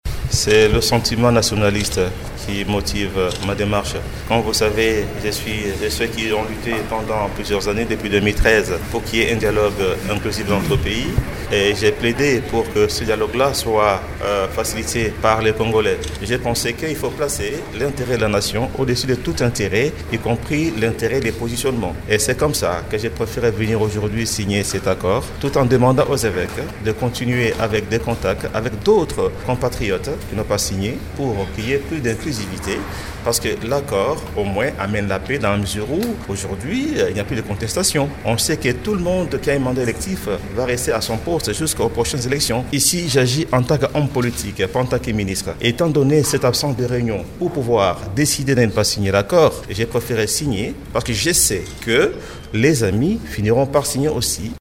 Vous pouvez suivre les explications de Steve Mbikayi dans cet extrait sonore: